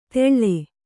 ♪ teḷḷe